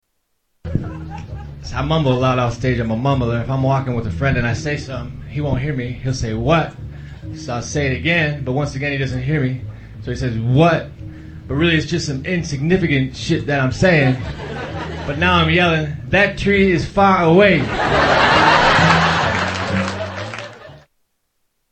Tags: Comedians Mitch Hedberg Sounds Mitchell Lee Hedberg Mitch Hedberg Clips Stand-up Comedian